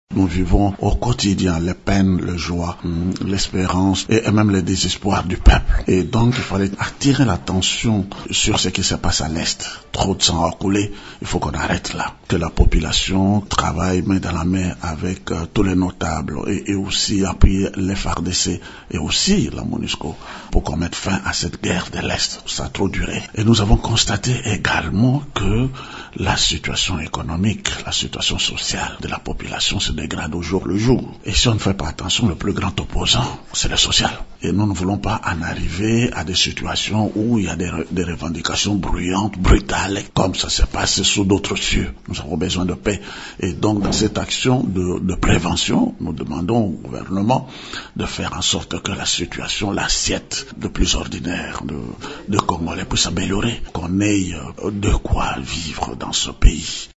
L’appel a été lancé samedi 11 janvier à Kinshasa au cours d’une conférence de presse.